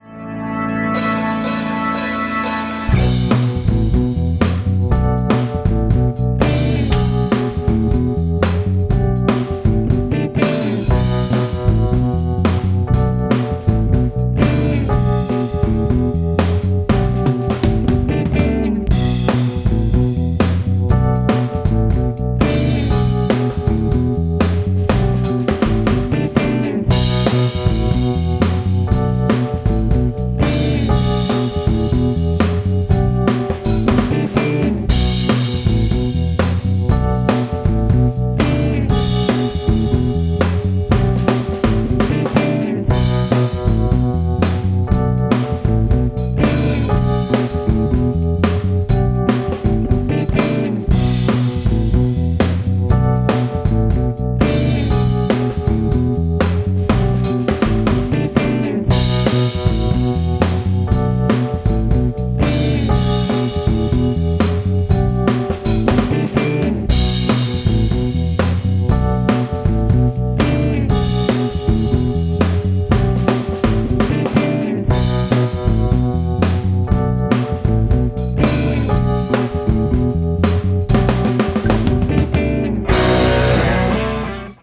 The chord progression is pretty simple:
Bm – Bm7 – E/B – G/B